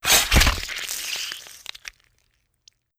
FueraDeEscala/Assets/Game Kit Controller/Sounds/Melee Sword Sounds/Melee Weapon Attack 33.wav at 84d8d08112b77e993f29f274bcf53ca52ae296ce
Melee Weapon Attack 33.wav